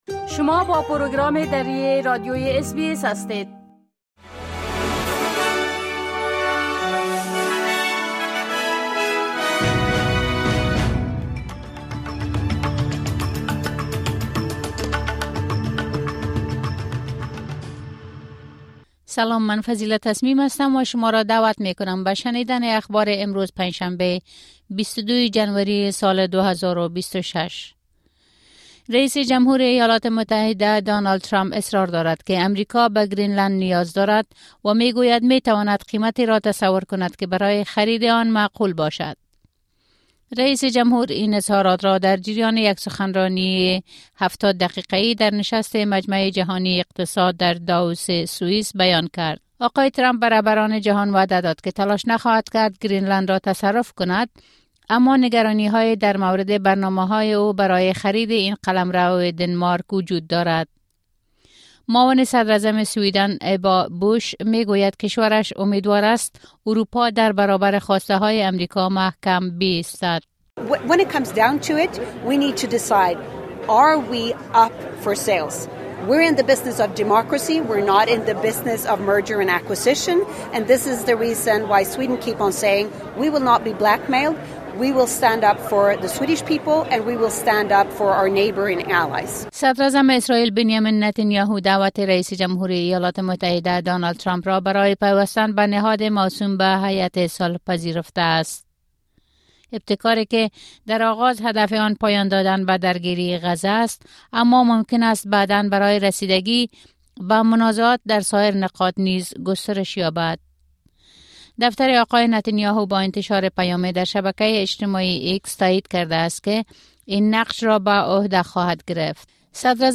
خلاصه‌ای مهم‌ترين خبرهای روز | ۲۲ جنوری